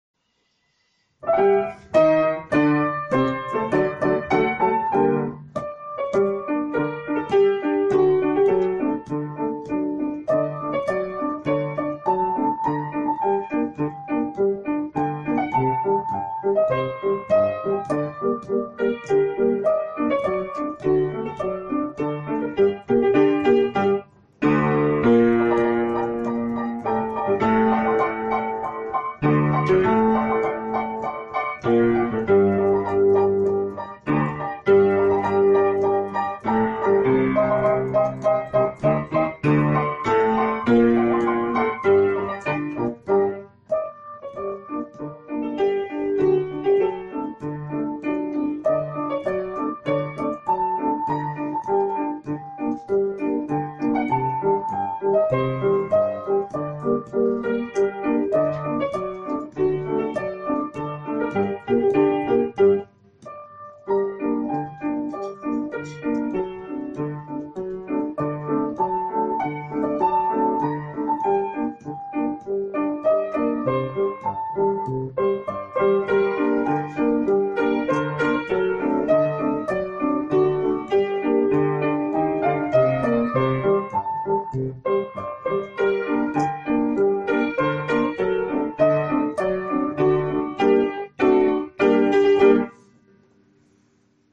Марш «Свобода России» (воспроизведено по изданному в США нотному тексту)